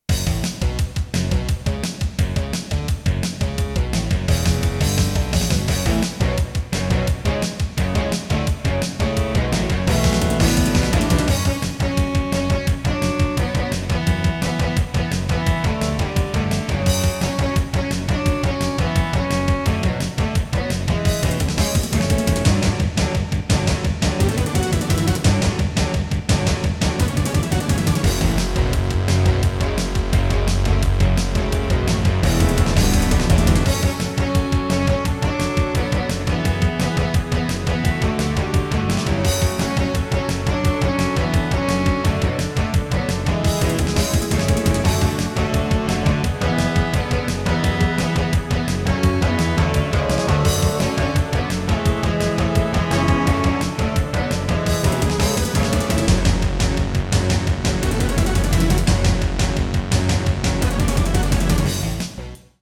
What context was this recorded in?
NEC xr385 (Yamaha DB60XG clone) * Some records contain clicks.